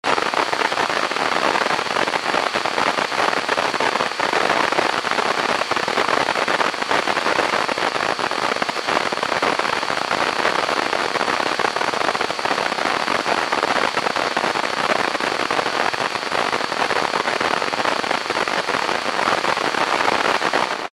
White noise listen online for adult sleep,listen and download for free
• Category: Sleep